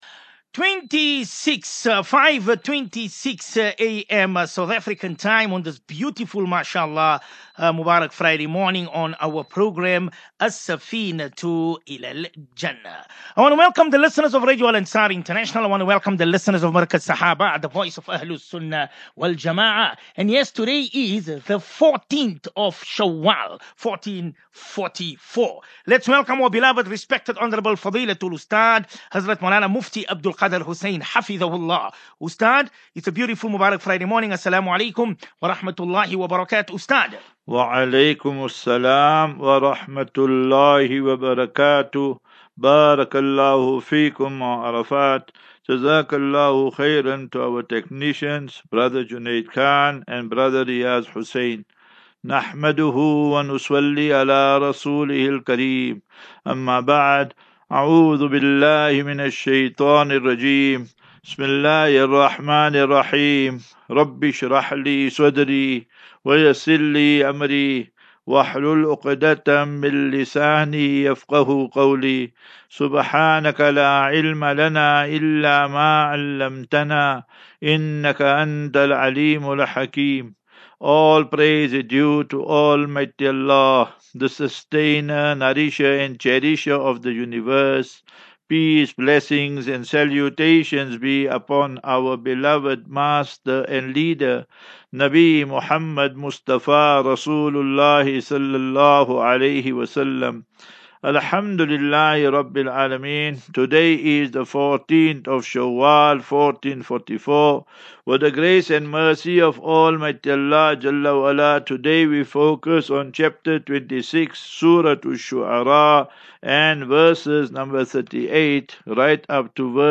View Promo Continue Install As Safinatu Ilal Jannah Naseeha and Q and A 5 May 05 May 23 Assafinatu